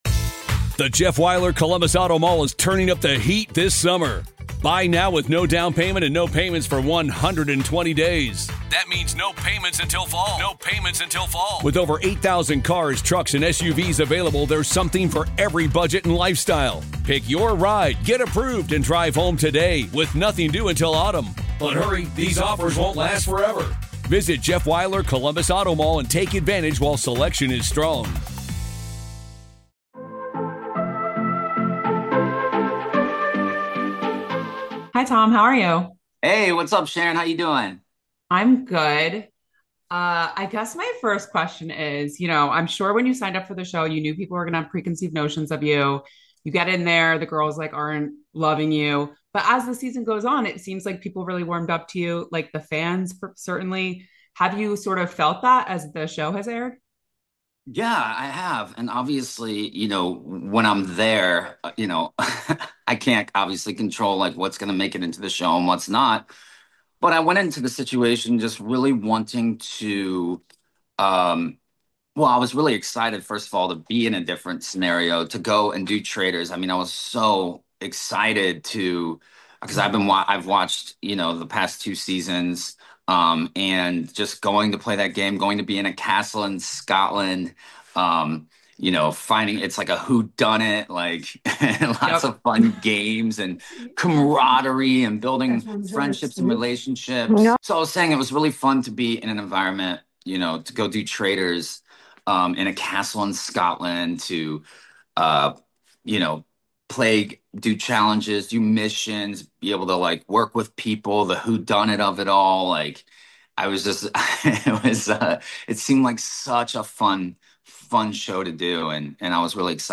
The Traitors Exit Interview: Tom Sandoval Addresses Sam's Slide Comments, Finding Redemption